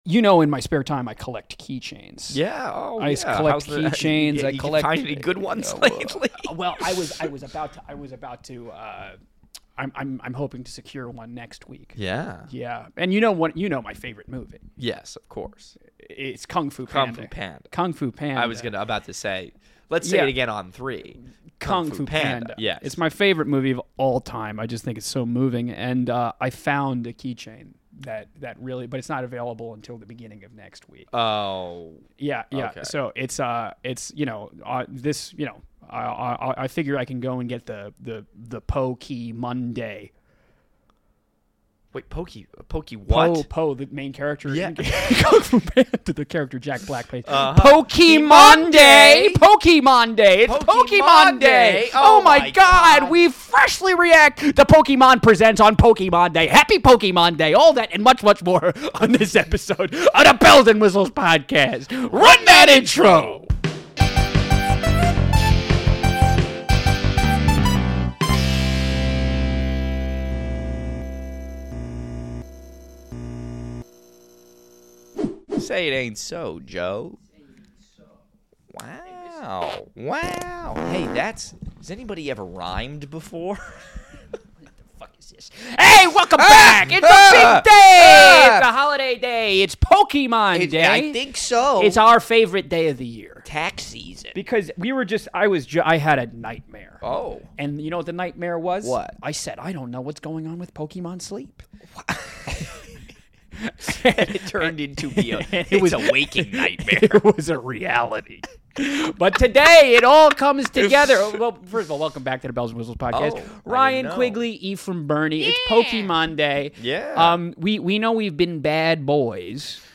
We live react to Pokemon Presents!